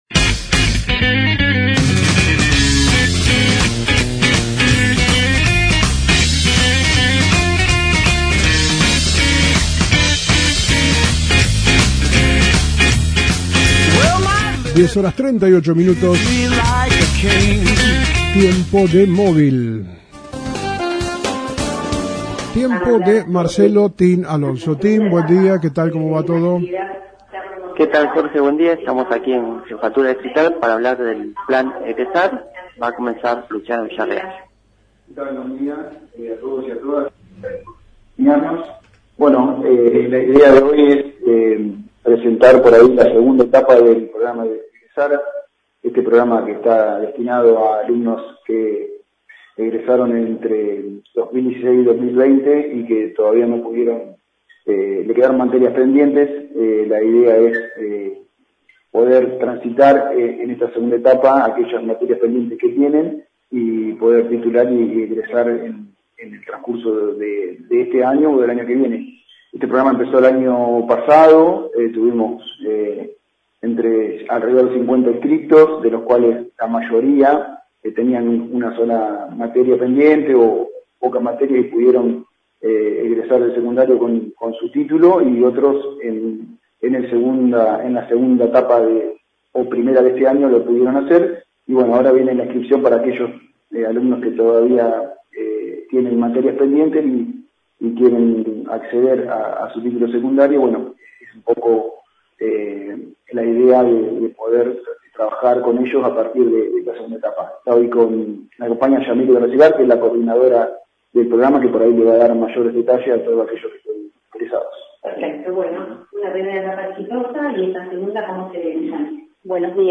Conferencia de Prensa - Jefatura Distrital